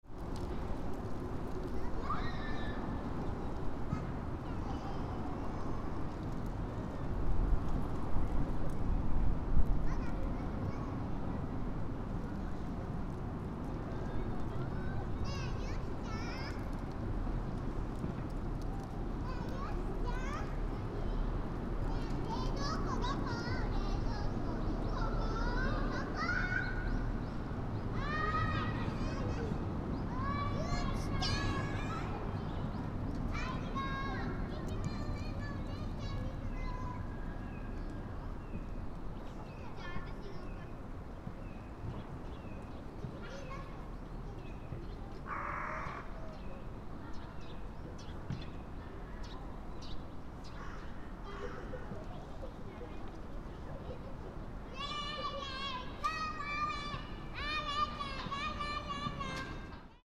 On the day 14 years after the quake in Shinhama Park, some children were playing around the play equipment, and some high school students were playing on the ground where they could use balls.
Maybe because the day was warmer than usual days in March, many people sat on the benches, and therefore, almost all benches in the park were occupied.
The twittering of birds and cawing of crows were heard well.